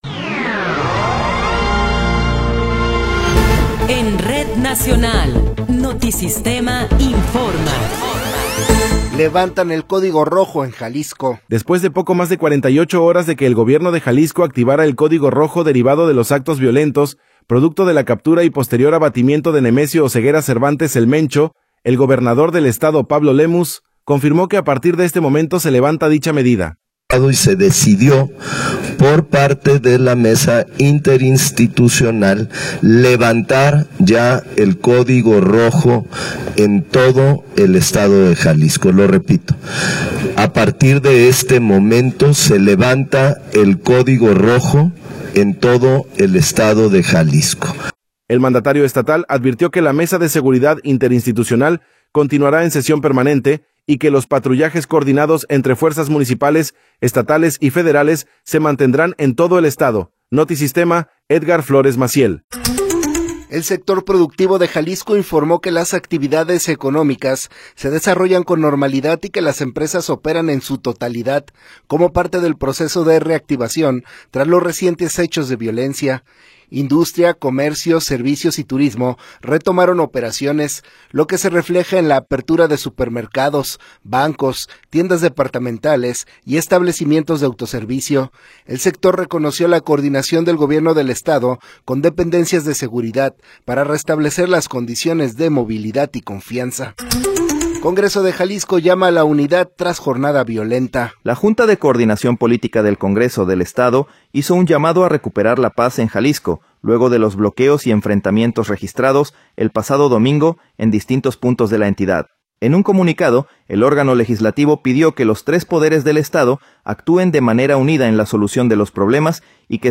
Noticiero 13 hrs. – 24 de Febrero de 2026